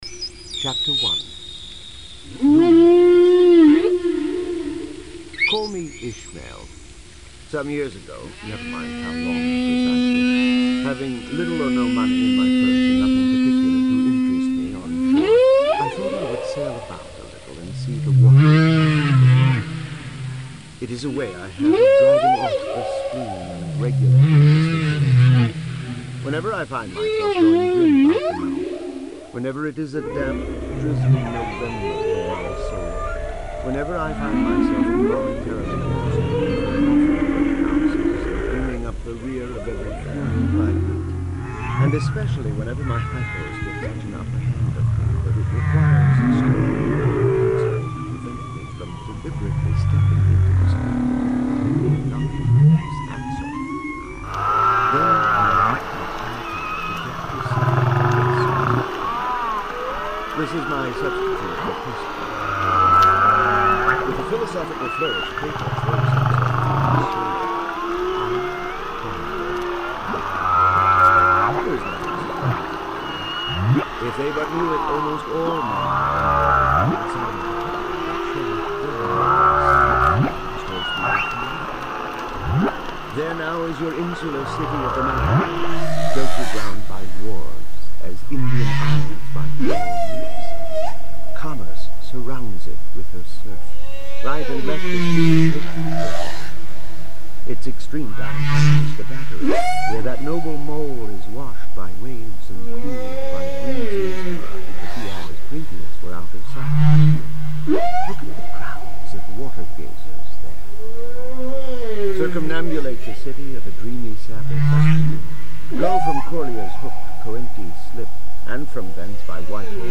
The speaker plays two tracks: the complete 23-hour audiobook of Moby Dick, and a recording of whale songs. The whales sing loudly enough to obscure the text but not so loud that they drown out the audiobook. Snippets of text can be heard but not clearly enough to follow the story.